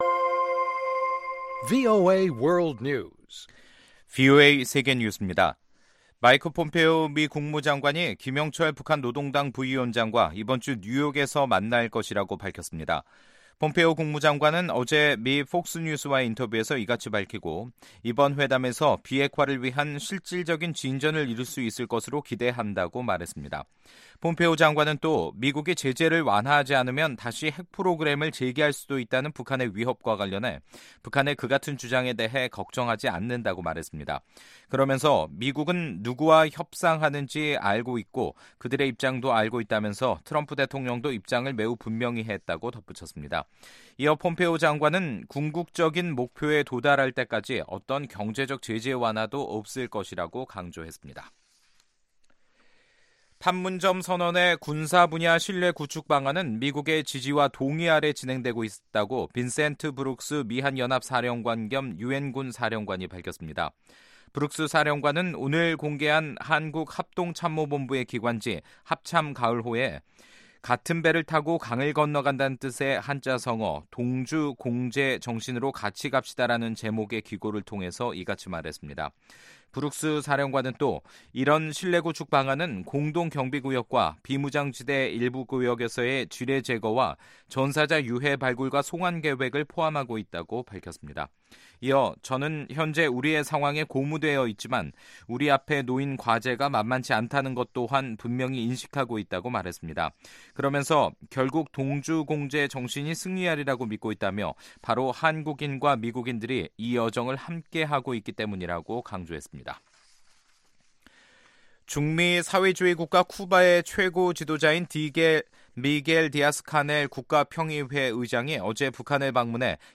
VOA 한국어 간판 뉴스 프로그램 '뉴스 투데이', 2018년 11월 5일 2부 방송입니다. 마이크 폼페오 미 국무장관은 이번 주 뉴욕에서 김영철 북한 노동당 부위원장과 만날 것이라고 확인했습니다. 오는 6일 유엔 인권이사회에서 열리는 중국 인권에 대한 보편적 정례검토(UPR)에서 탈북자 강제 북송 문제도 제기될 것이라고 전망되고 있습니다.